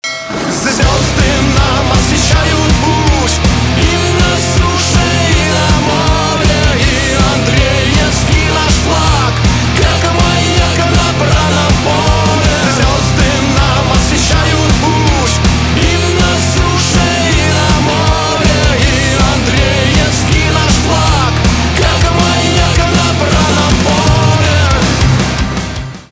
русский рок
гитара , барабаны